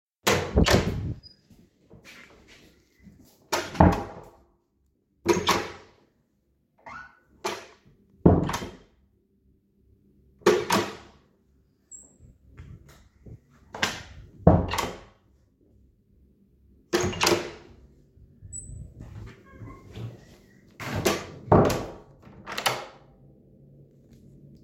door.mp3